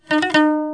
Pulling skill is pressing on the string as accent combined with legato but the quality of the sound is different; the accented tone just vibrated abruptly stops, The sound of the mordent is similar to hiccough; it can express the feeling of being upset and frustrated.
Ngón giật: là cách nhấn trên dây như ngón nhấn luyến nhưng tính chất âm thanh khác: âm được nhấn tới vừa vang lên liền bị tắt ngay một cách đột ngột, âm thanh tiếng giật nghe như tiếng nấc, diễn tả tình cảm day dứt, thương nhớ.